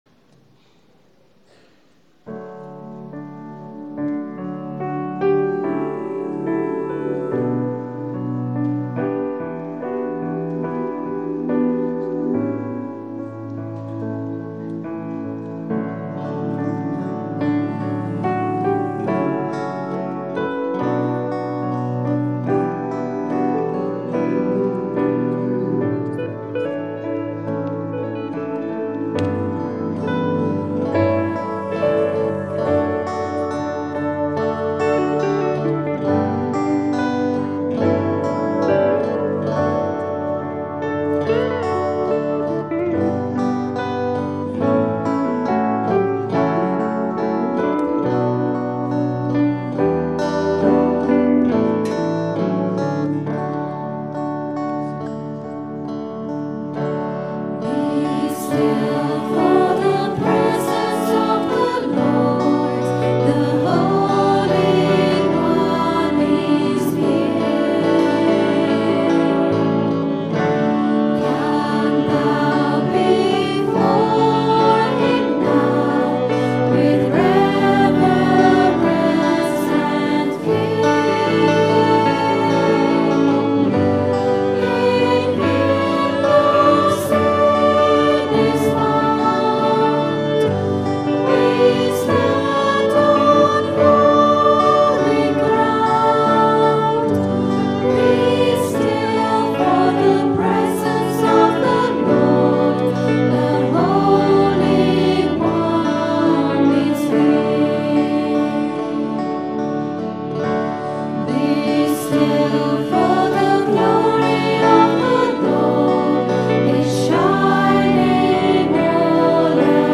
Recorded on a Zoom H4 digital stereo recorder at 10am Mass Sunday 14th November 2010.